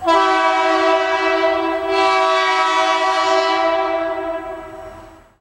horn_xlong.ogg